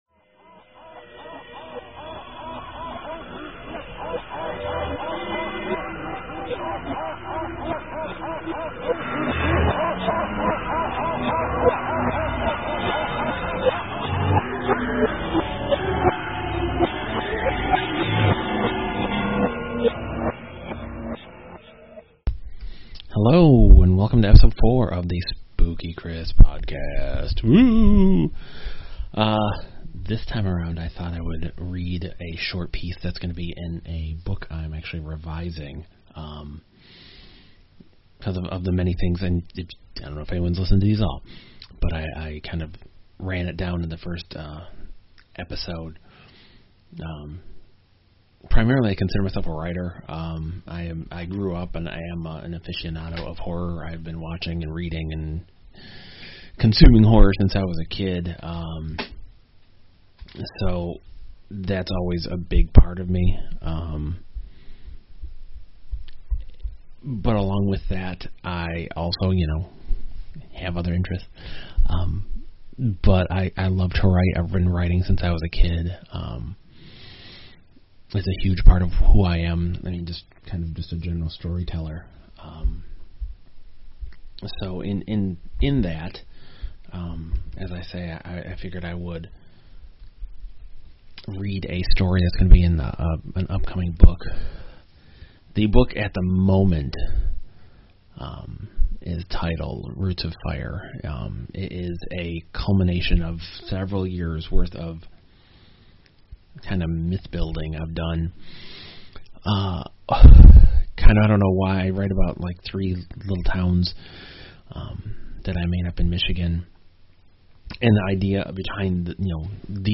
Decided to read one of the short stories to be included in a future book.